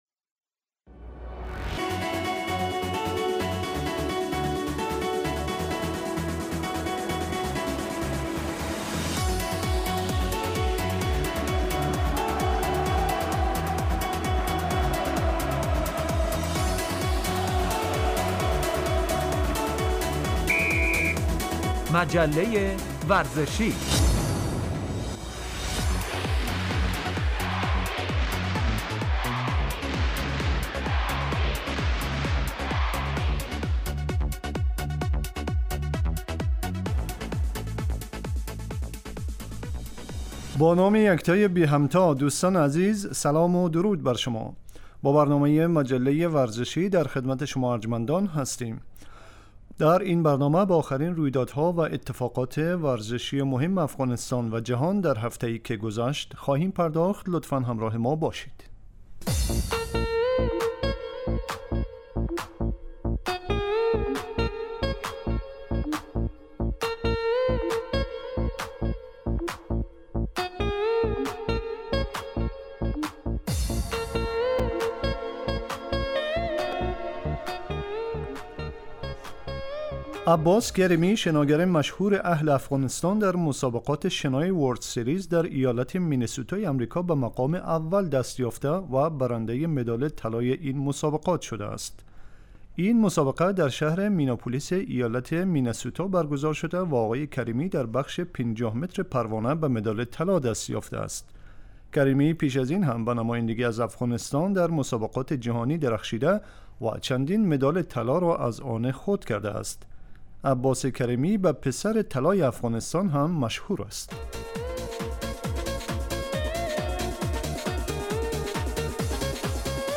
آخرين اخبار و رويدادهاي ورزشي افغانستان و جهان در هفته اي که گذشت به همراه گزارش و مصاحبه وبخش ورزش وسلامتي